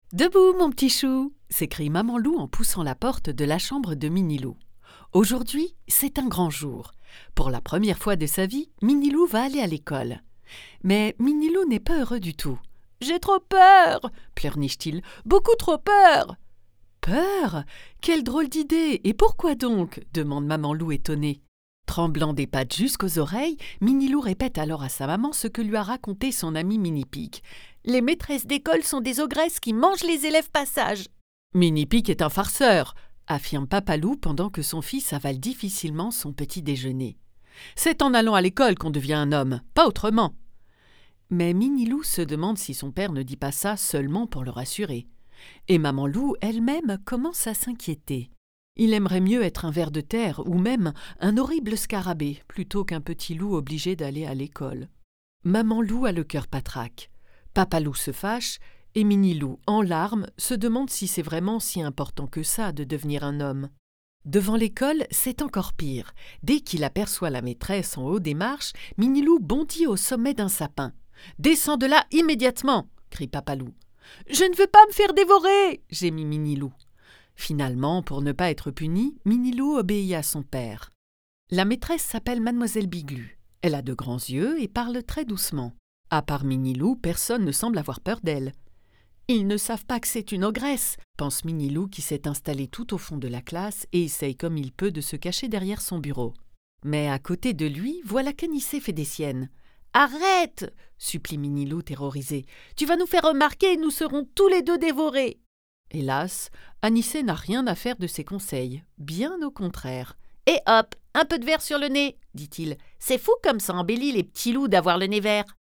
Livre pour enfant
Voix off